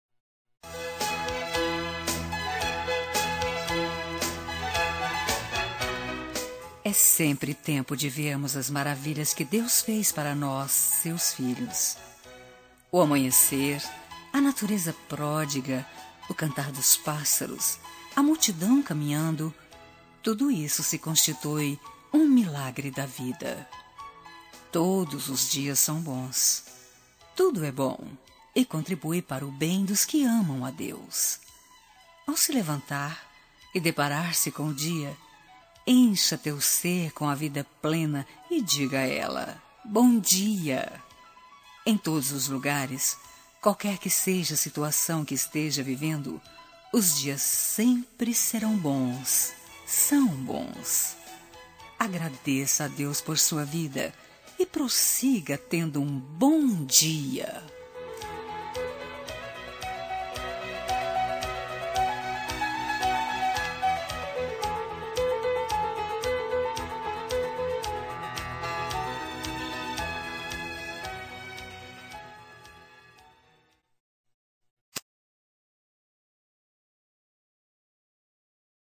Telemensagem de Bom dia – Voz Feminina – Cód: 6321 – Romântica
6321-dia-rom-fem.mp3